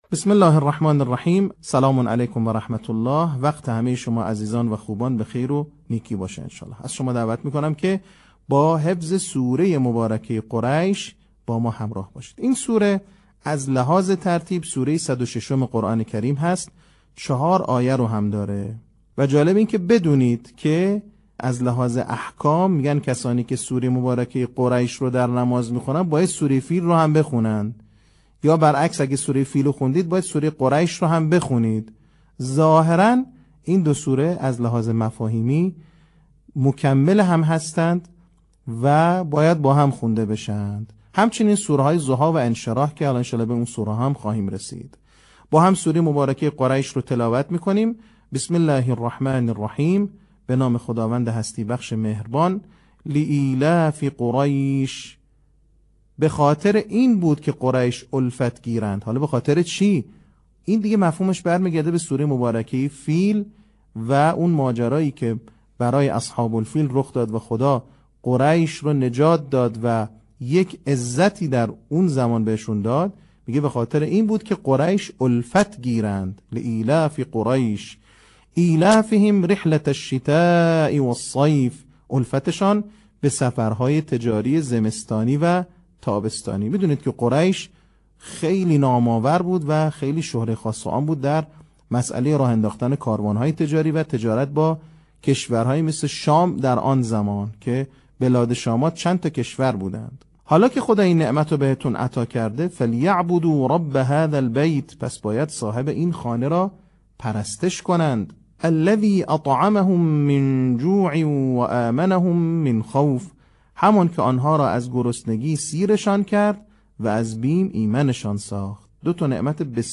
صوت | آموزش حفظ سوره قریش